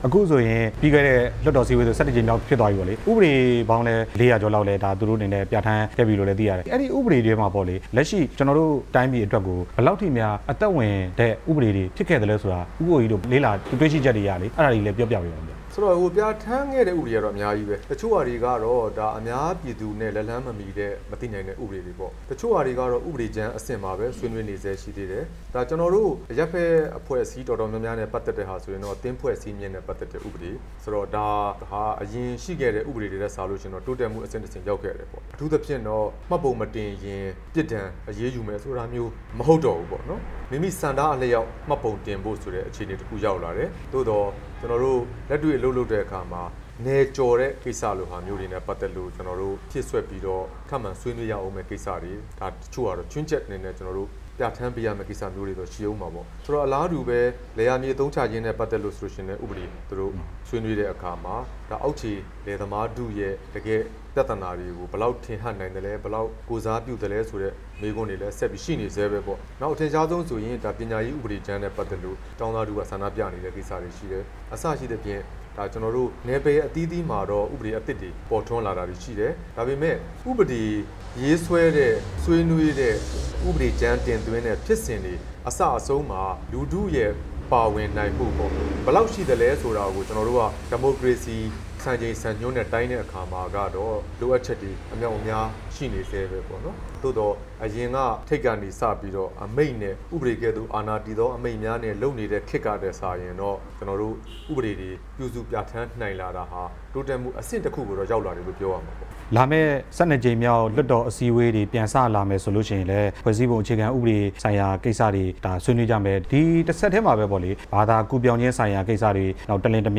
လက်ရှိနိုင်ငံရေးအခြေအနေနဲ့ အနာဂါတ်အလားအလာ ဦးကိုကိုကြီးကို မေးမြန်းချက်
ဦးကိုကိုကြီးနဲ့ မေးမြန်းချက်အပြည့်အစုံ